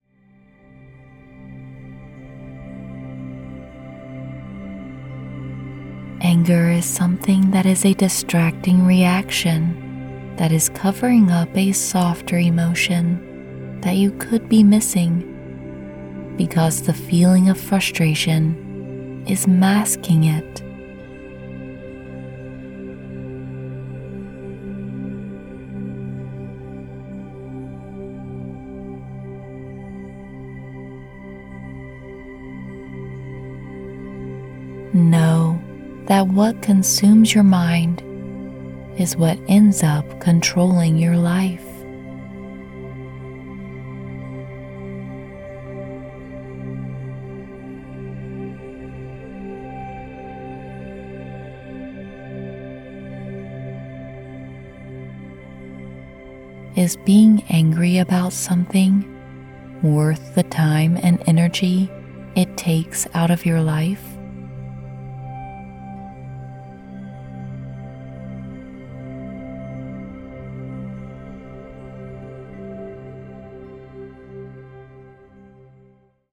No one enjoys feeling angry…our audio hypnosis can help you manage your anger in a variety of ways and show you how to choose how you emotionally want to be!